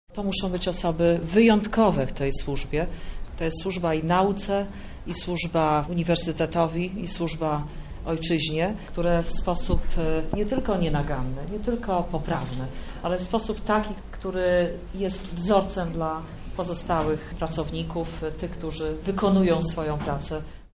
Na Uniwersytecie Marii Curie Skłodowskiej odbyła się uroczystość wręczenia krzyży zasługi za długoletnią prace i wybitne osiągnięcia.
By zostać nagrodzonym pracownik powinien się wyróżniać wieloma cechami – mówiła wojewoda lubelska Jolanta Szołno – Koguc.